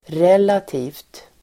Uttal: [r'el:ati:vt]